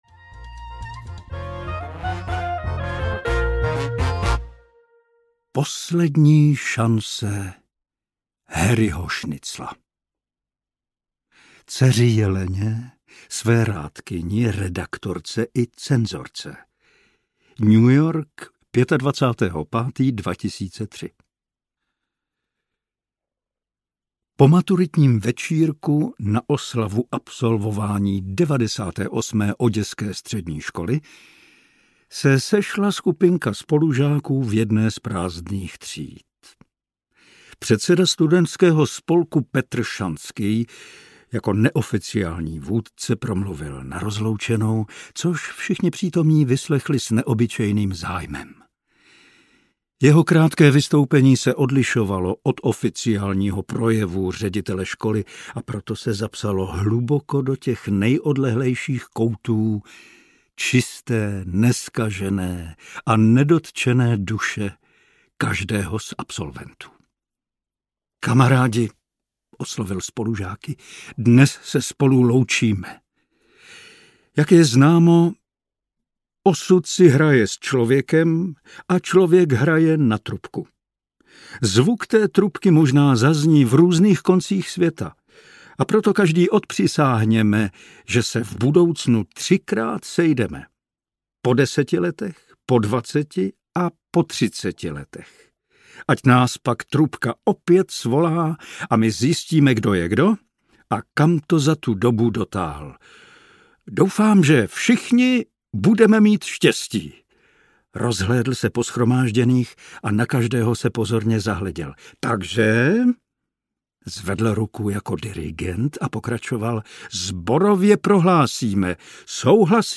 Audiokniha Povídky z Oděsy a odjinud 2, jejímž autorem je Mark Polevoj: Hrdinové příběhů v citlivém podání Pavla Soukupa prožívají své (ne)všední osudy mezi Oděsou, válečnou vřavou v Evropě a New Yorkem.
Ukázka z knihy
• InterpretPavel Soukup